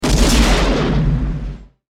Shotgun 2.mp3